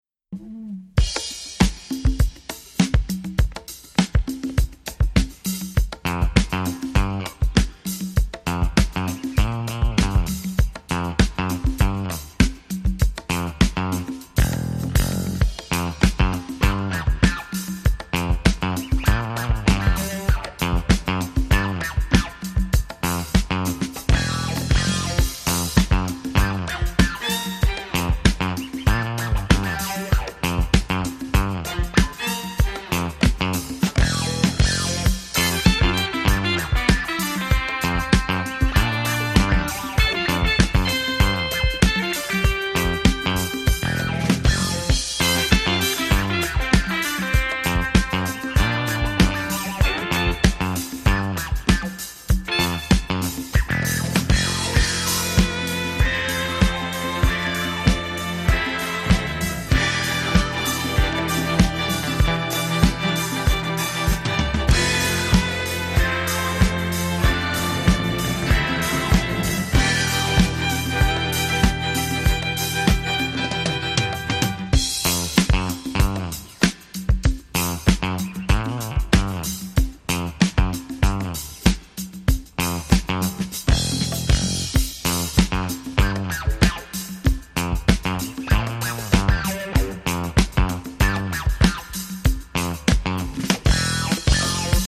The majestic, powerfully emotive